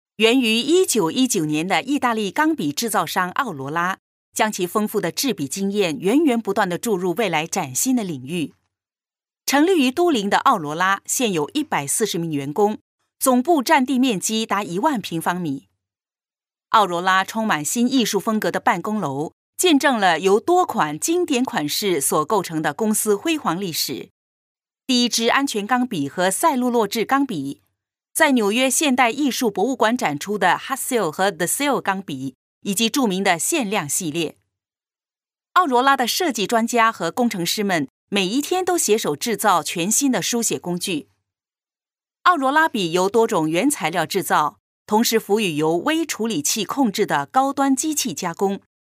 Professionelle chinesische Sprecherin für Werbung, TV, Radio, Industriefilme und Podcasts.
Professional female chinese voice over artist.